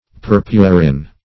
Search Result for " purpurin" : The Collaborative International Dictionary of English v.0.48: Purpurin \Pur"pu*rin\, n. (Chem.) A dyestuff resembling alizarin, found in madder root, and extracted as an orange or red crystalline substance.